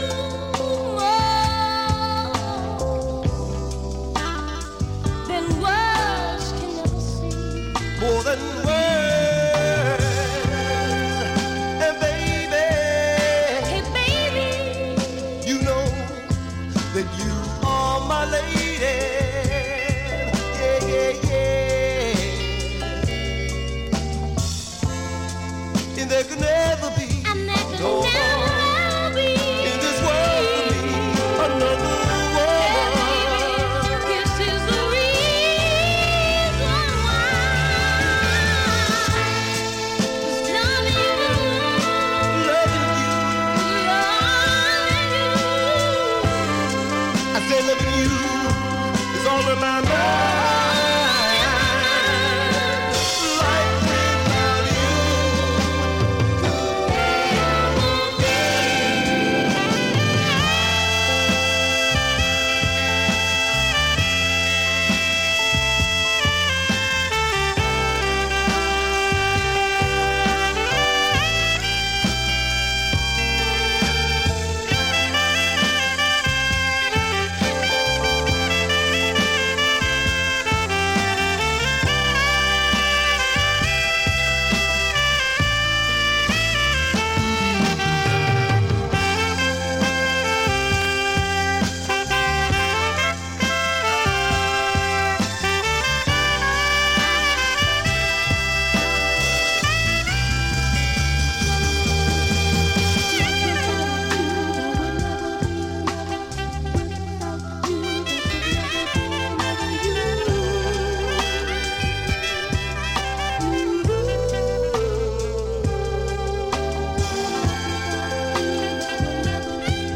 Disco Funk Boogie